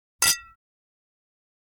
звон бокалов (41 Кб)